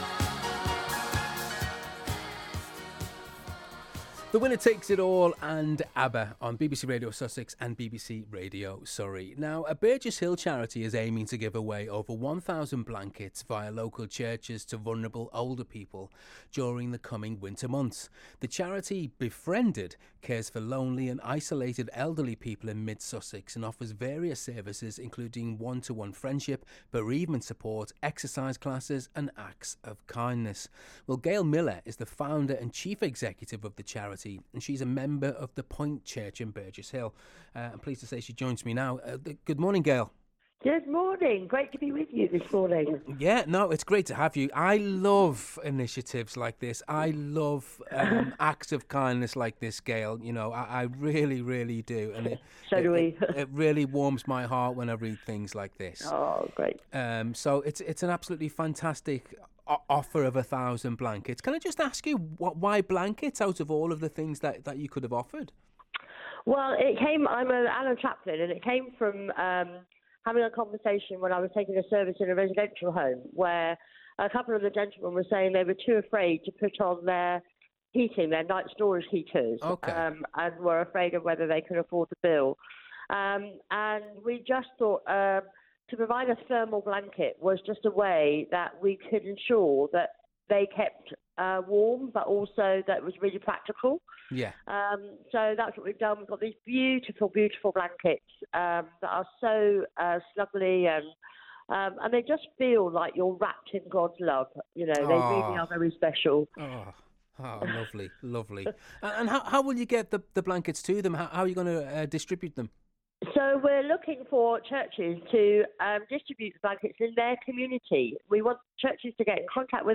BBC Radio Sussex during this week which includes the World Day of Kindness.